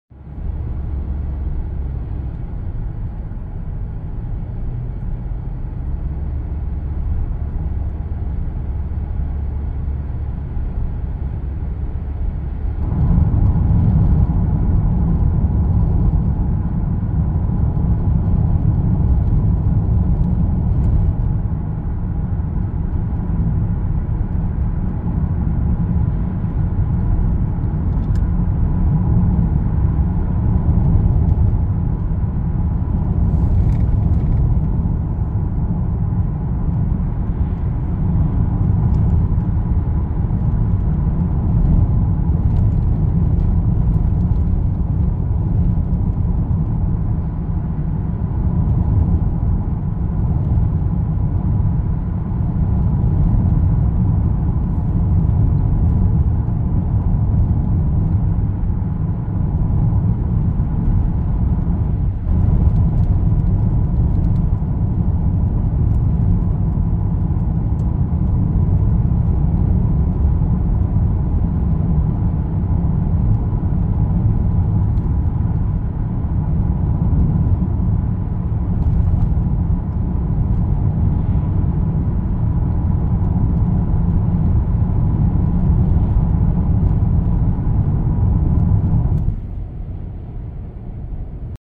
Звуки движения в автомобиле по трассе, шум во время езды с разной скоростью
16. Звук езды в машине по шумному асфальту
ezda-po-shumnomu-asfalitu.mp3